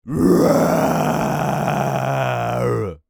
Male_Low_Growl_02.wav